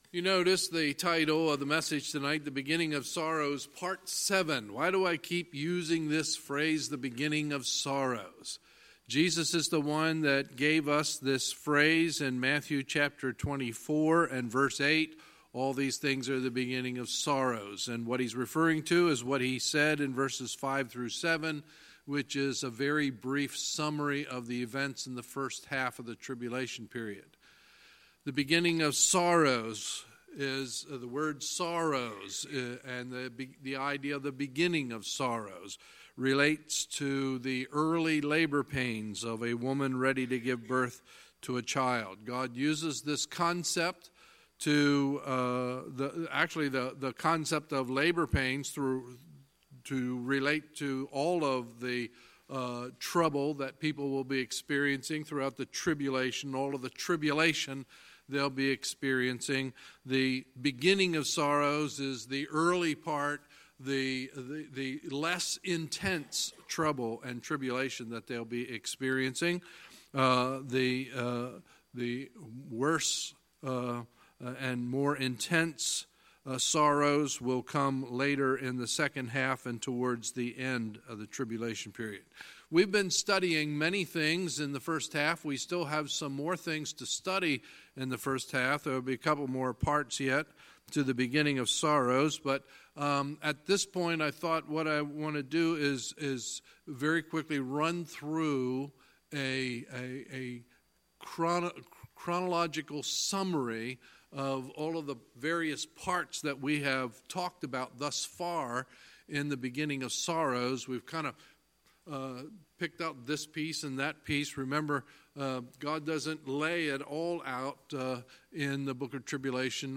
Sunday, January 27, 2019 – Sunday Evening Service
Sermons